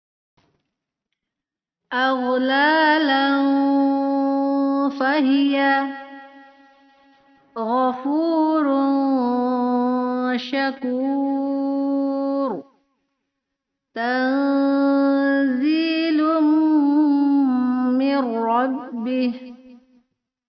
Tanwin sembilan sembilan, arahkan dengungnya ke huruf yang di depannya.